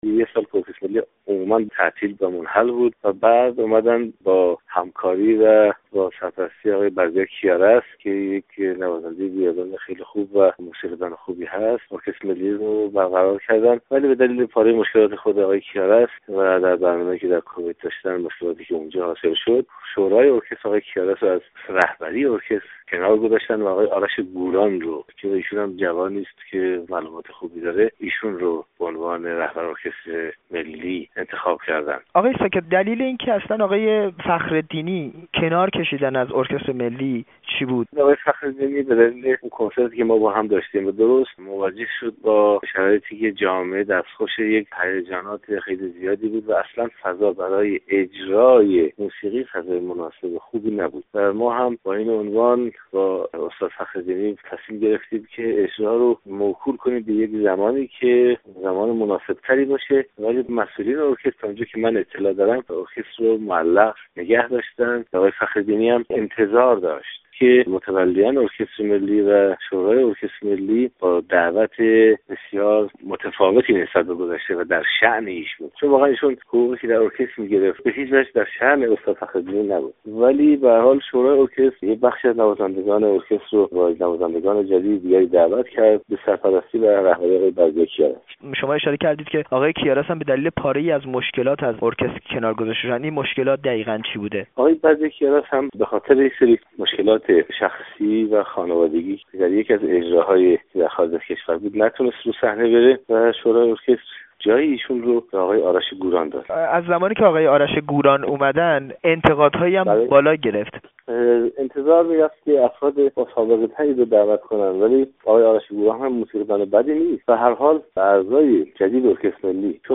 گفت و گو با کیوان ساکت، آهنگساز، درباره تعطیلی دوباره ارکستر ملی ایران